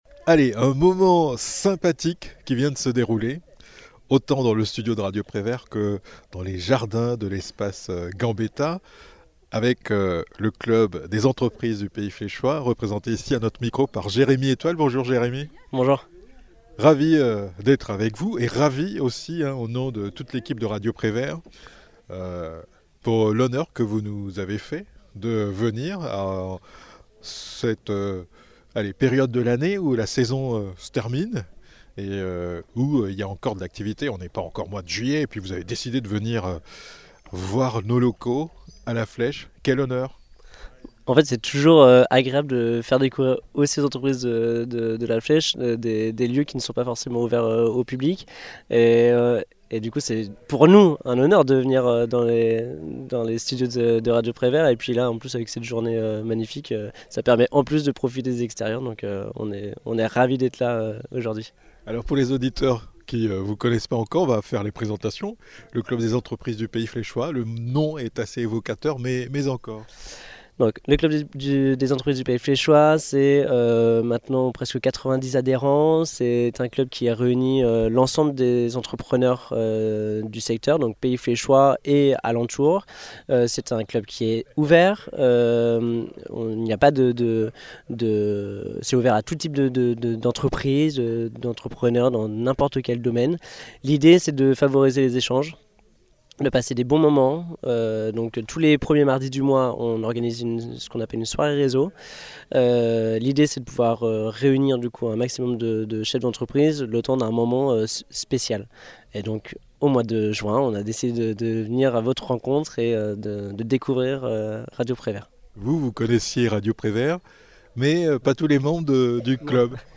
Une soirée conviviale au cours de laquelle, l'équipe de Radio Prévert a notamment présenté les spécificités de la radio à la quarantaine de personnes présentes. Après un temps d'échanges sur le thème "Comment communiquez-vous sur votre entreprise ?", la soirée s'est terminée par un cocktail dinatoire.